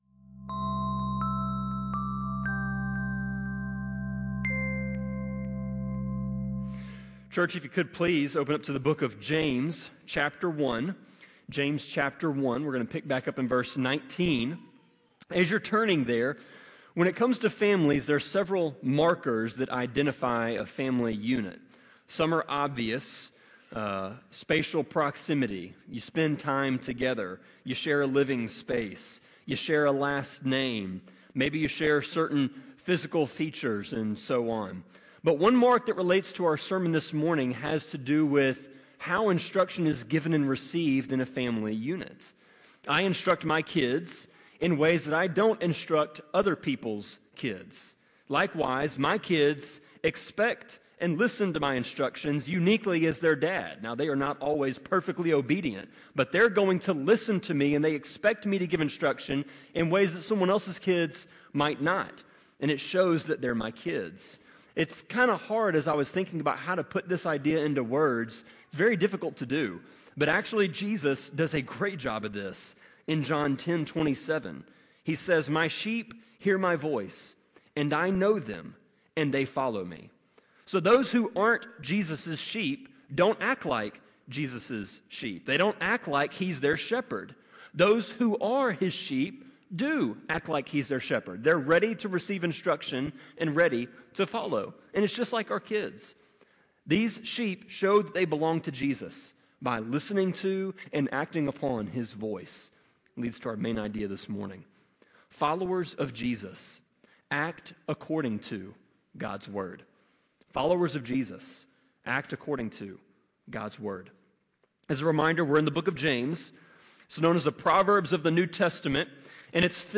Sermon-25.6.1-CD.mp3